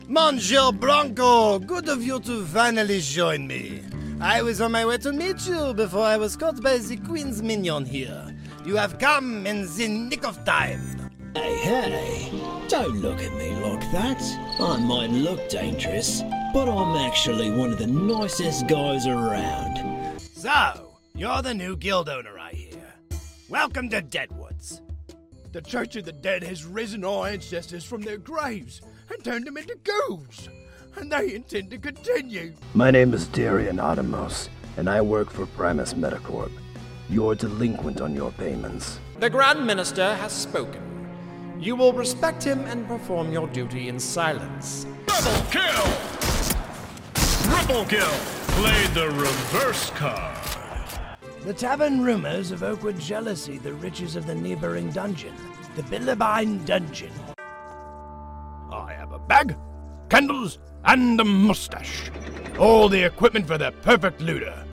Teenager, Young Adult, Adult, Mature Adult
australian | character
british rp | character
cockney | character
GAMING 🎮